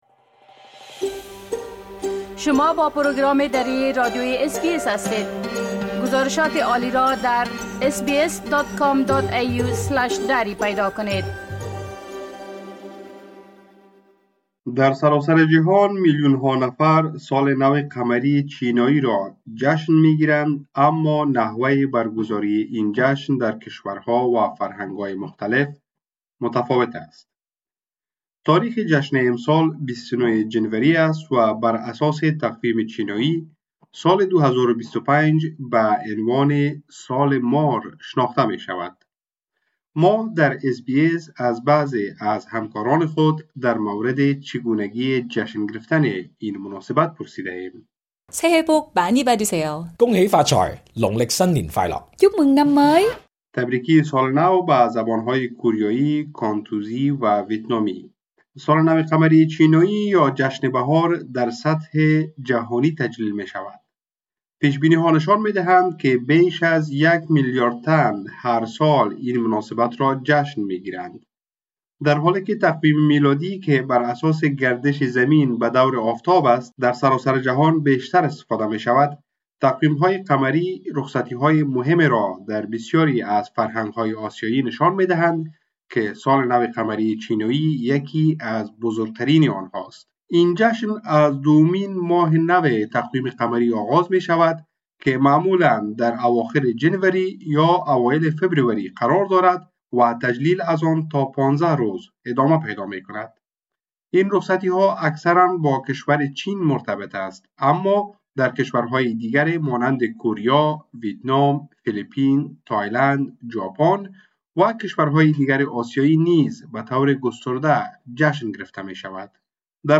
ما، در اس‌بی‌اس از بعضی از همکاران خود در مورد چگونگی جشن گرفتن این مناسبت پرسیده ایم.